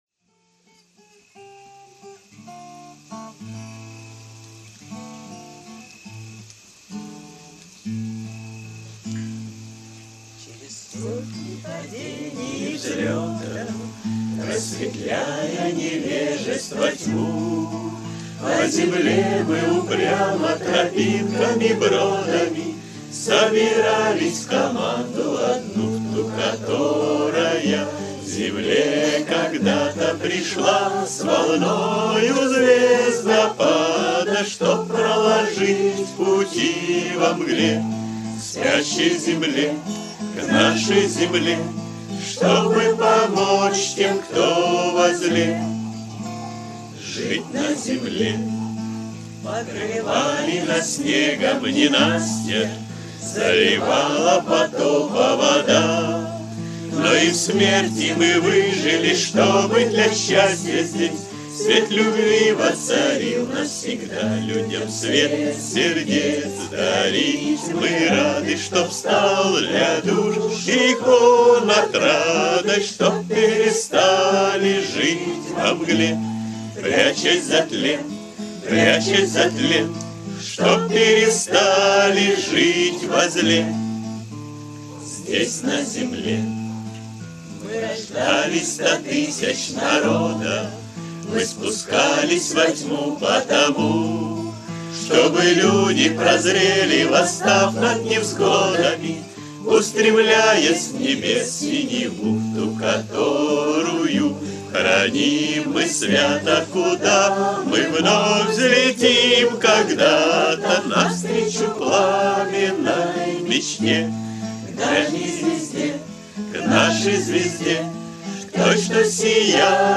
кавер-версия на мотив песни